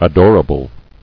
[a·dor·a·ble]